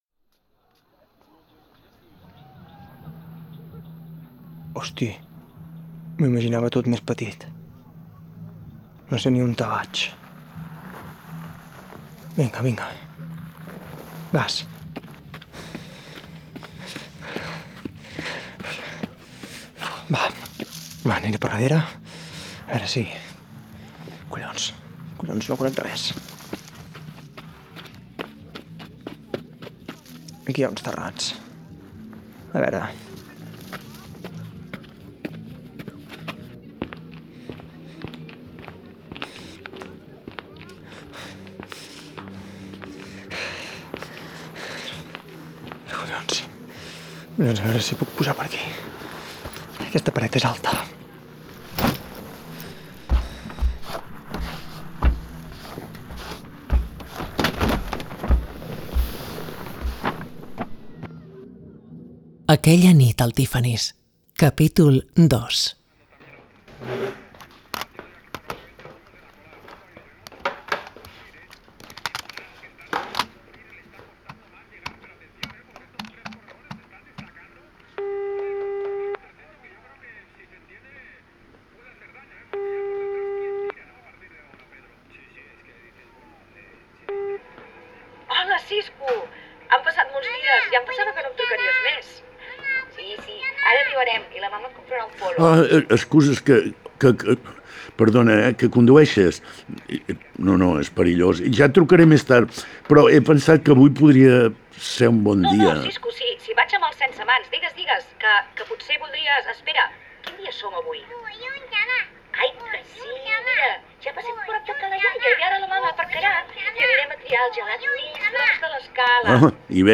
Aquella nit al Tiffany’s és una sèrie de ficció sonora basada en fets reals que ens transporta a la Costa Brava dels anys seixanta, en plena dictadura